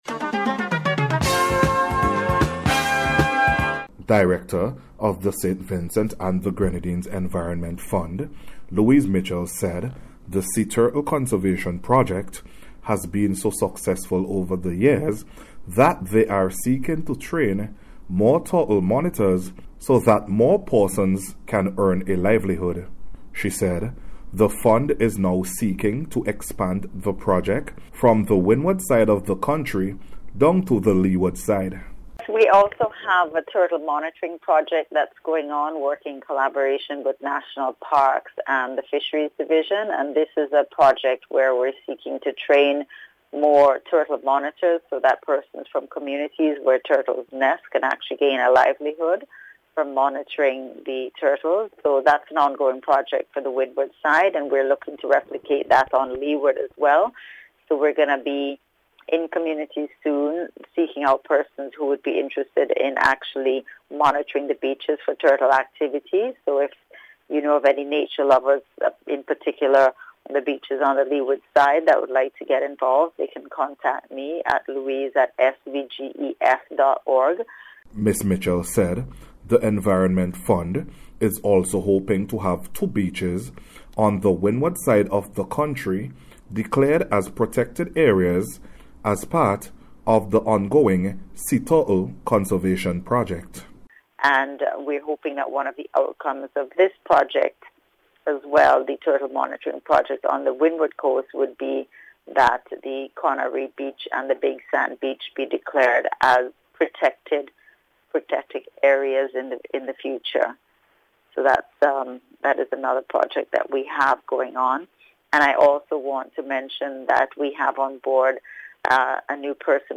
SEA-TURTLE-CONSERVATION-REPORT.mp3